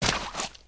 dirt01gr.ogg